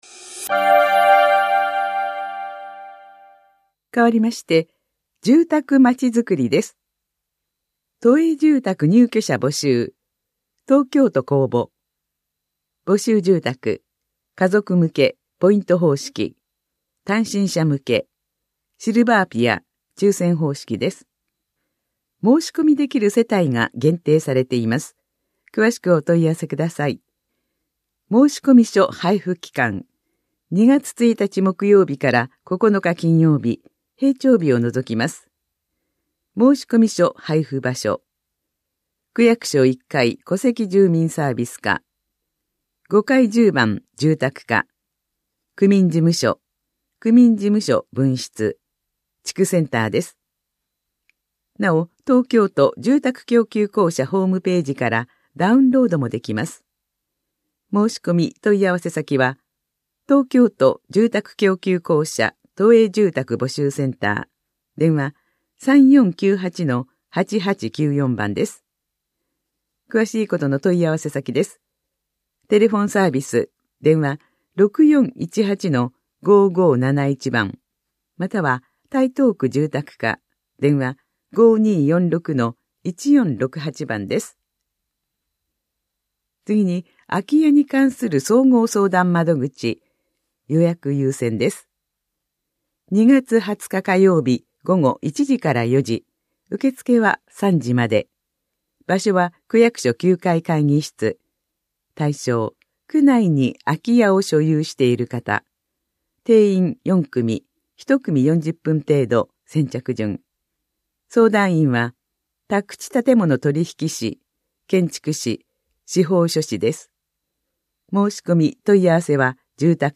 広報「たいとう」令和6年1月20日号の音声読み上げデータです。